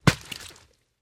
Звук: арбуз упал на землю